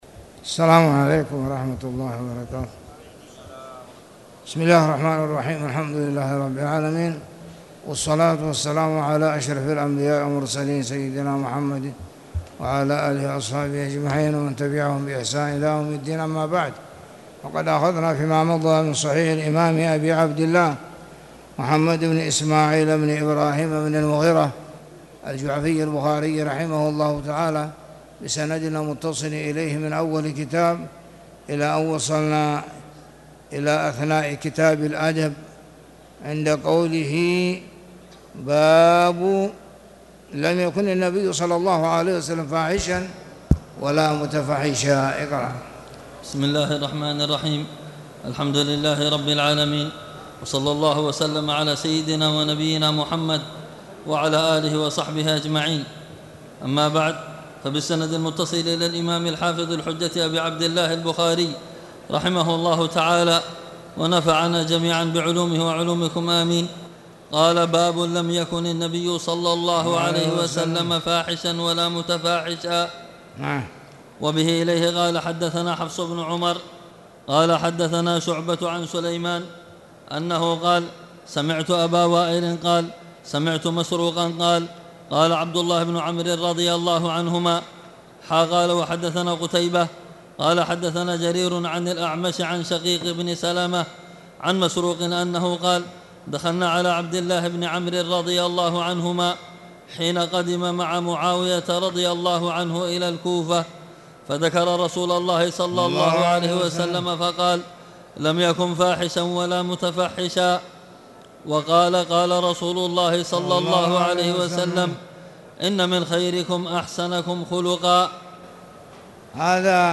تاريخ النشر ٢٩ جمادى الأولى ١٤٣٨ هـ المكان: المسجد الحرام الشيخ